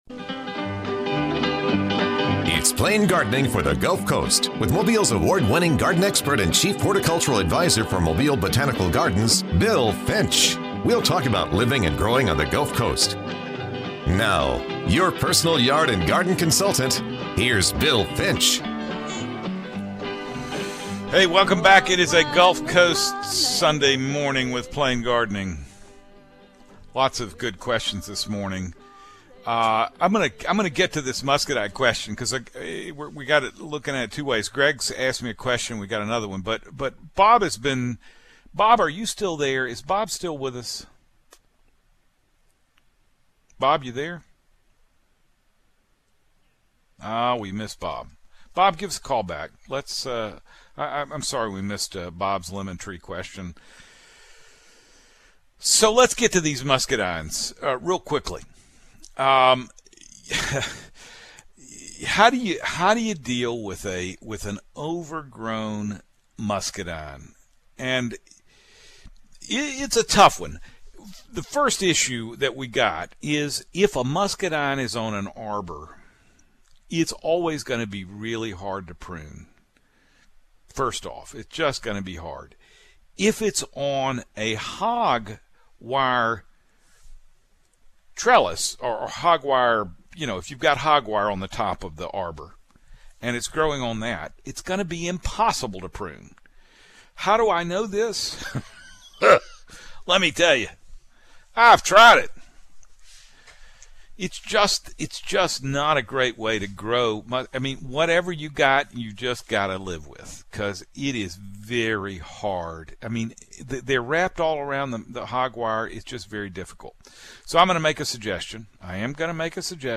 weekly gulf coast garden show